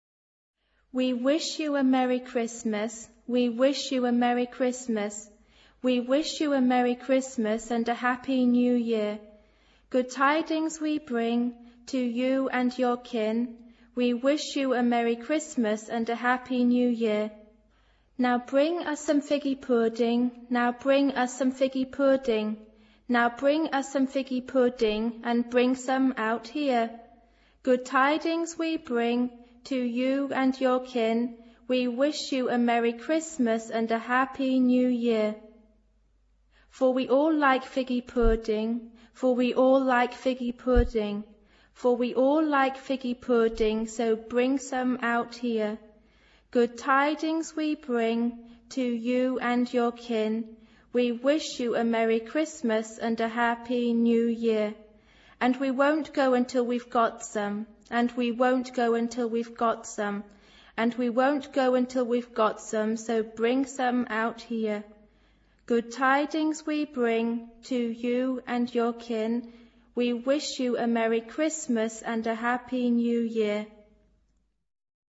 Género/Estilo/Forma: Sagrado ; Canción de Navidad
unspecified voicing (3 voces Coro mixto O iguales )
Instrumentos: Teclado (1)
Tonalidad : sol mayor